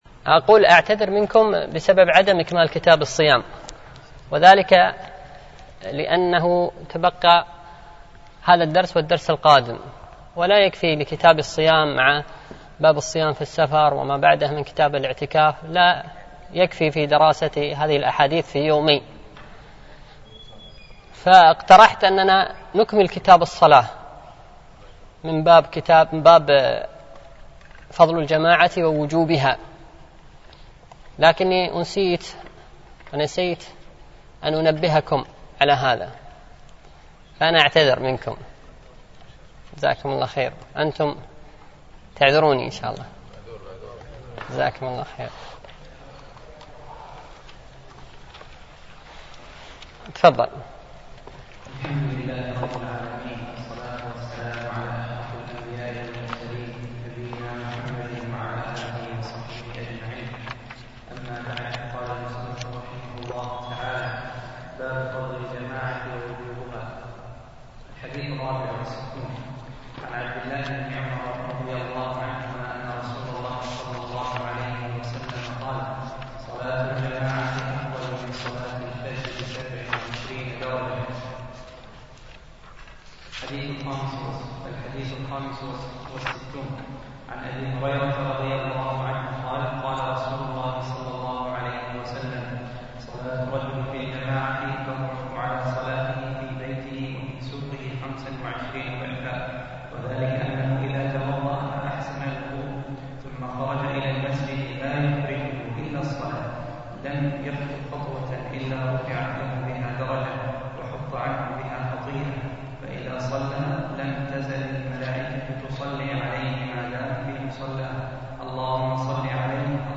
شرح عمدة الأحكام ـ الدرس التاسع عشر